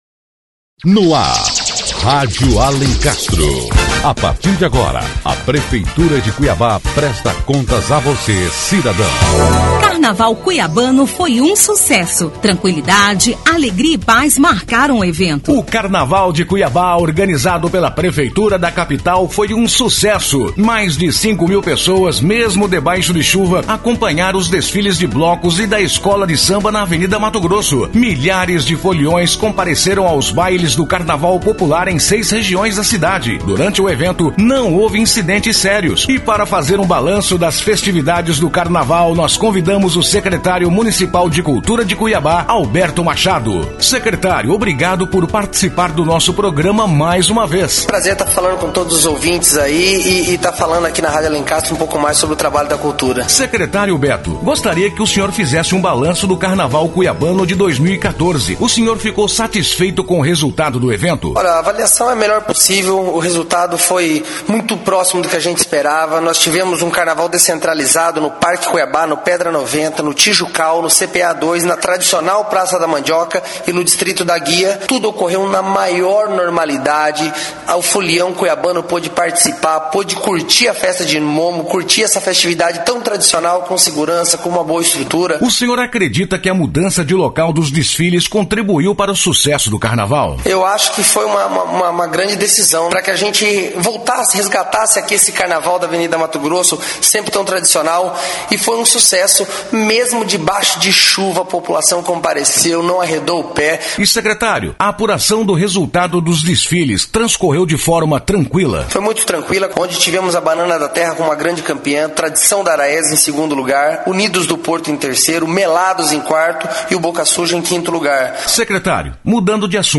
O Secretário Municipal de Cultura, Alberto Machado, comenta sobre o sucesso do carnaval.